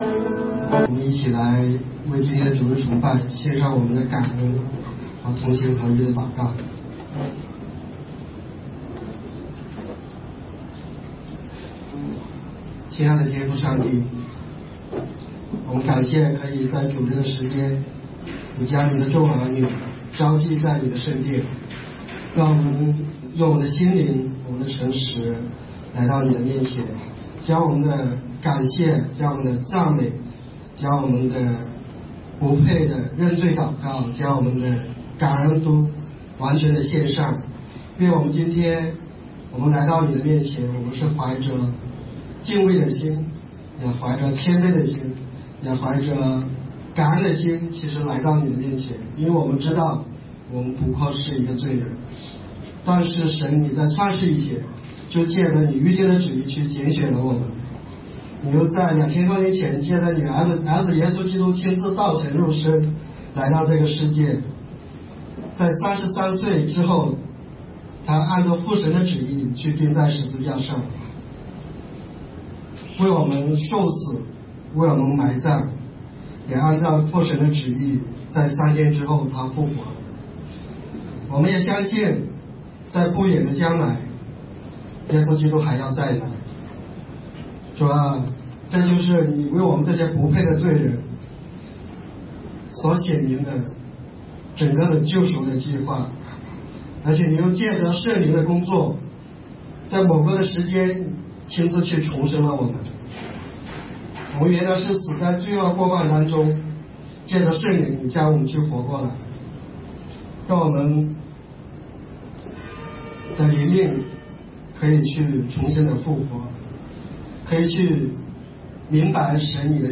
马太福音第47讲 弥赛亚的受难与复活IV 2018年5月20日 下午3:55 作者：admin 分类： 马太福音圣经讲道 阅读(6.5K